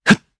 Esker-Vox_Jump_jp.wav